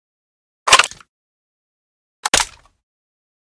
58aea60d01 Divergent / mods / M24 and M98B Reanimation / gamedata / sounds / weapons / librarian_m24 / reload.ogg 11 KiB (Stored with Git LFS) Raw History
reload.ogg